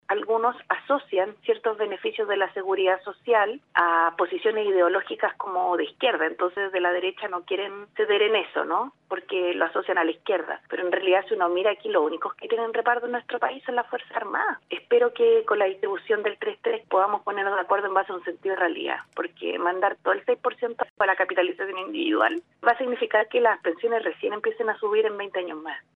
En conversación con el “Podría ser Peor”, aseguró que uno de los obstáculos políticos es que desde la derecha no quieren ceder en que existan mecanismos de seguridad o reparto, porque los asocian a posiciones ideológicas de izquierda y eso ha sido en parte, lo que ha tenido trabada la discusión.